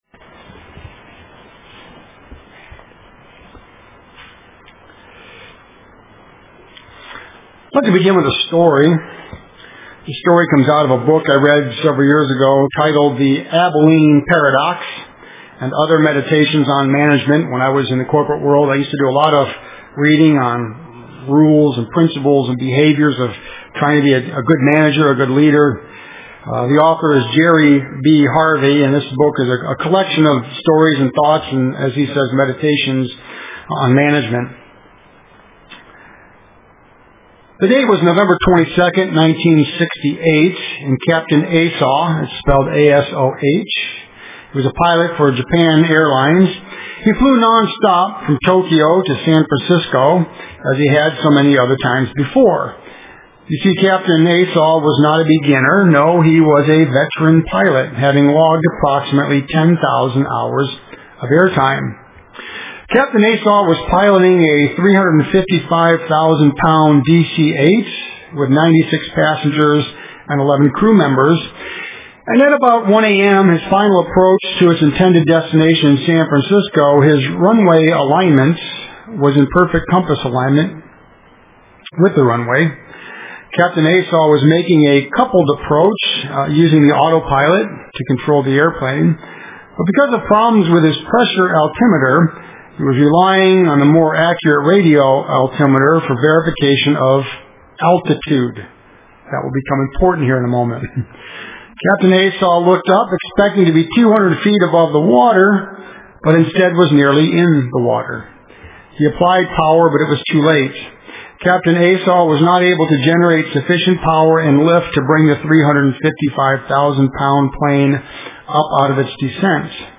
Print Truth and Mercy UCG Sermon Studying the bible?